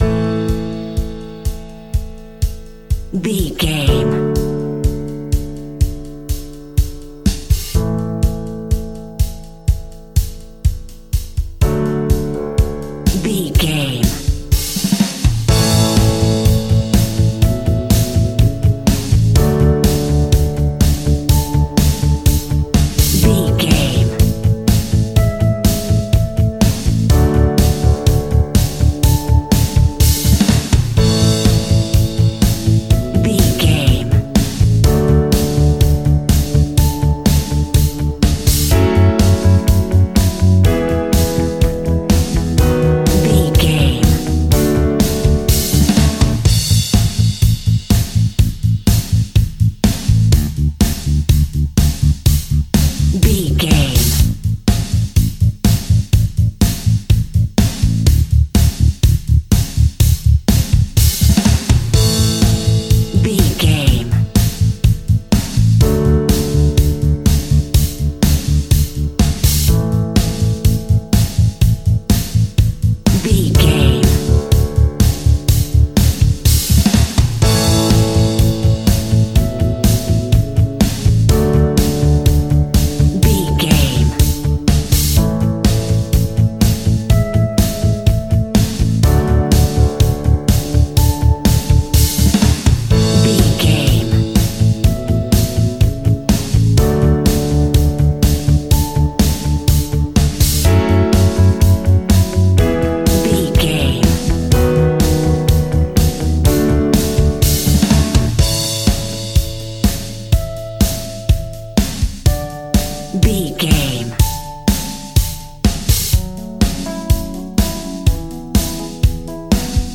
Ionian/Major
pop rock
fun
energetic
uplifting
instrumentals
indie pop rock music
guitars
bass
drums
piano
organ